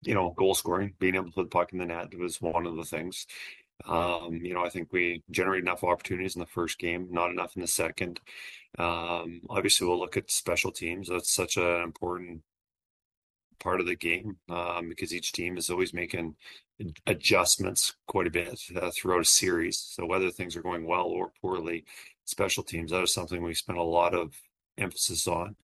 After only tallying 19 shots on goal all game, Oilers coach Kris Knoblauch says his team needs to work on getting more opportunities to score.